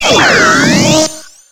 Cri de Motisma dans Pokémon X et Y.